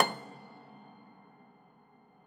53o-pno18-C4.wav